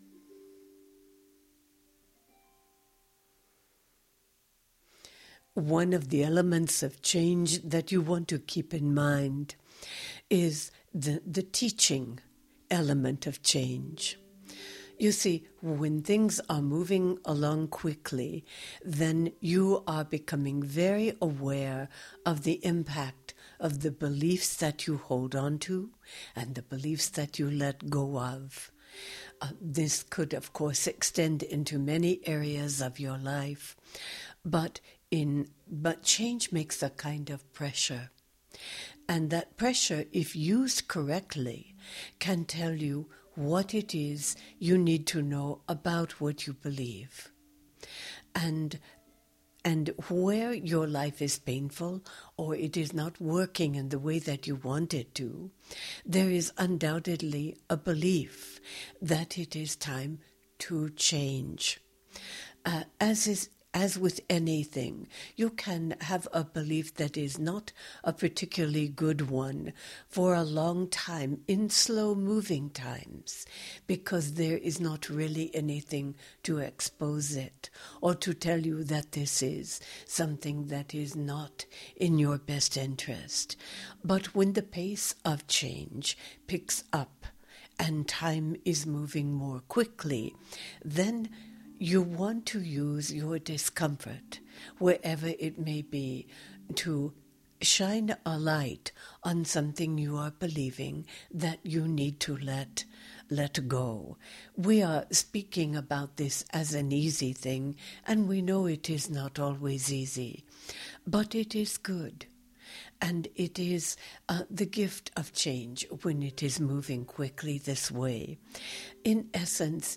Genre: Meditation.